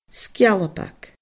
sgealbag /sgʲaLabag/